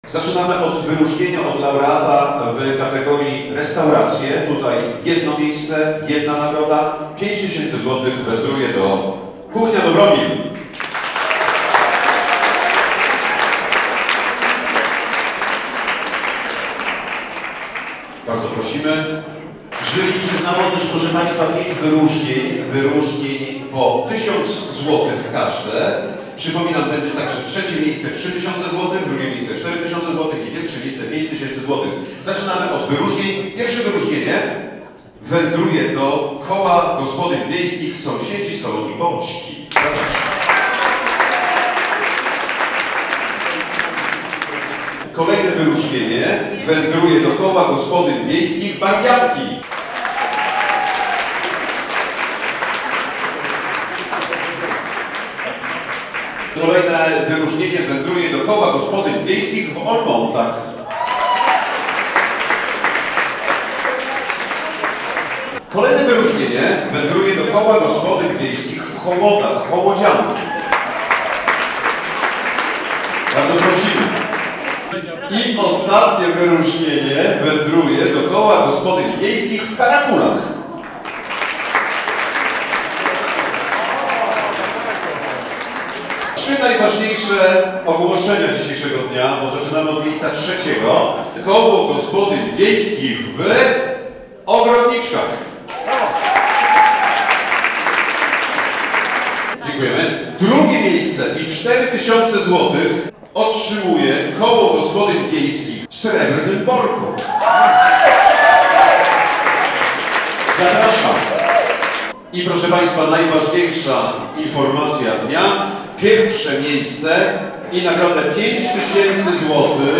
Zakończyła się IV edycja konkursu "Tradycyjny stół wigilijny” - relacja